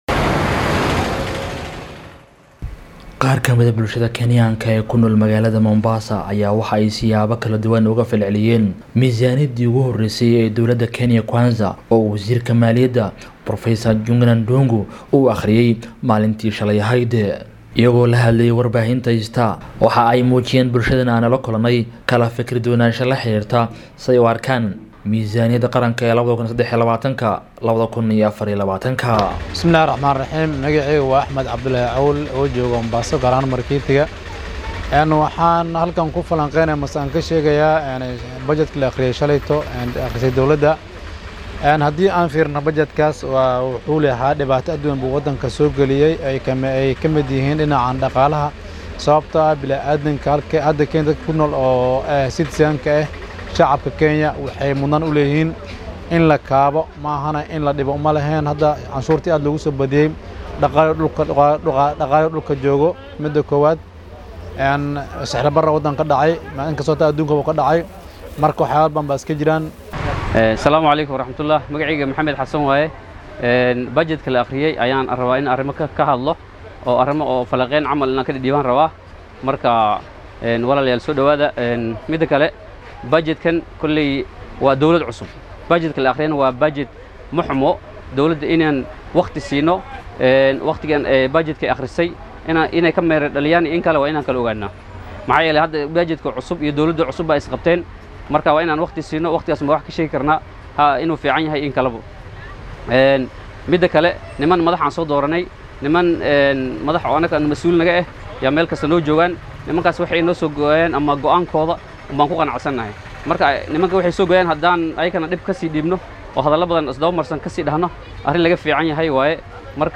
DHAGEYSO:Shacabka Mombasa oo ka hadlay miisaaniyadda shalay la soo bandhigay
Dadweynaha ku nool ismaamulka Mombasa ayaa aragtidooda ka dhiibtay sida ay u arkaan miisaaniyadda dhaqaale ee ay shalay dowladda dhexe soo saartay.